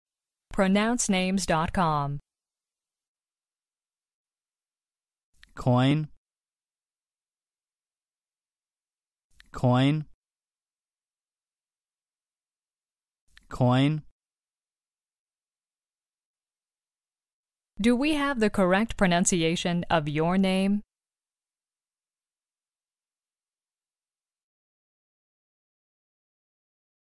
How_to_Pronounce_Coin_-_PronounceNamescom.mp3